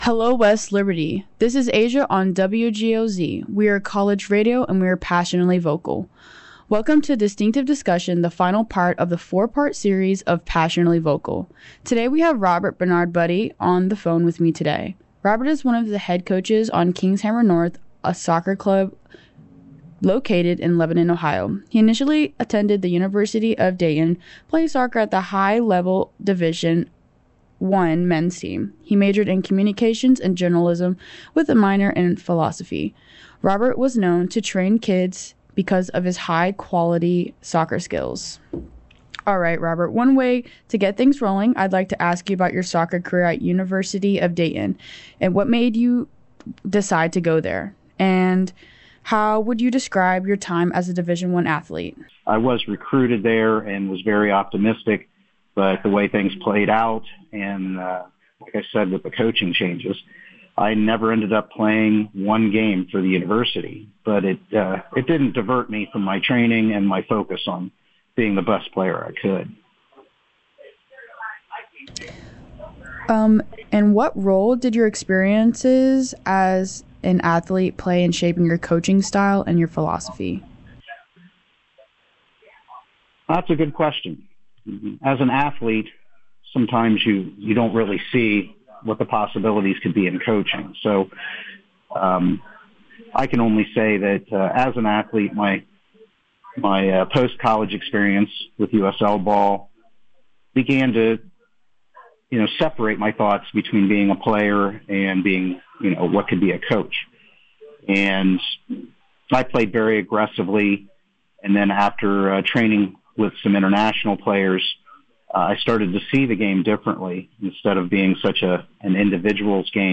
Interview
over the phone